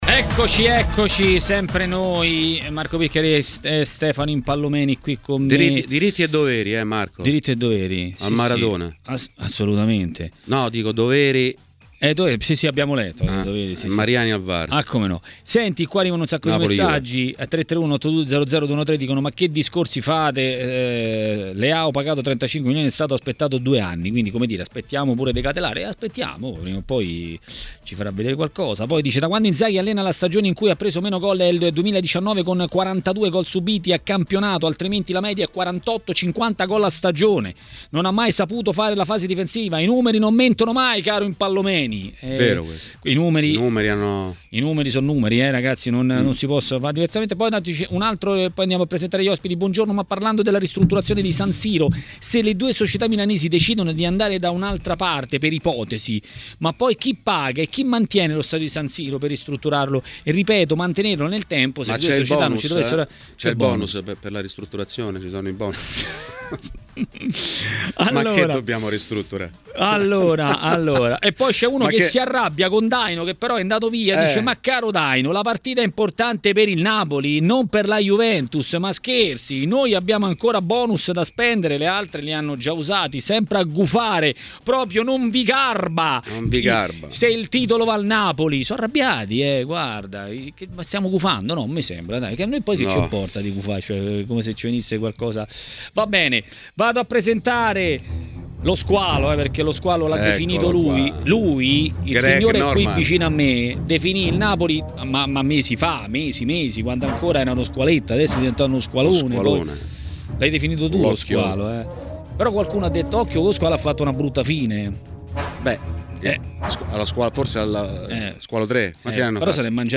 A commentare le notizie del giorno a TMW Radio, durante Maracanà, è stato l'ex calciatore e tecnico